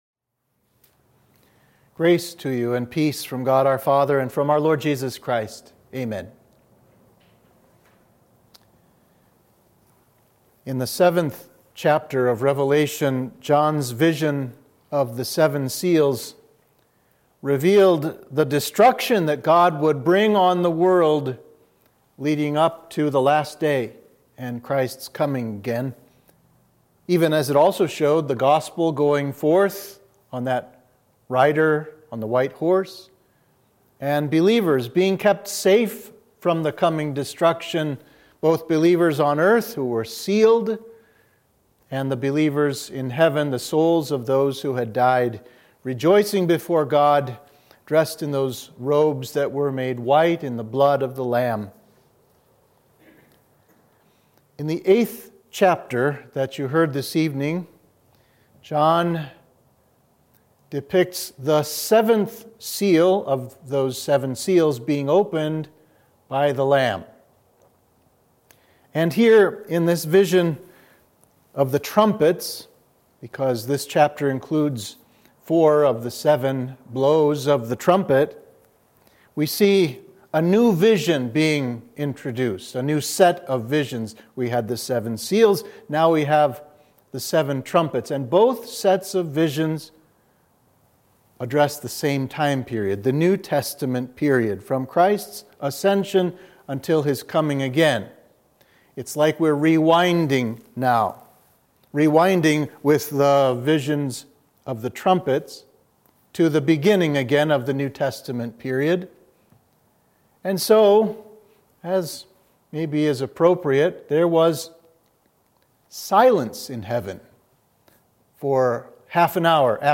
Sermon for Midweek of Easter 1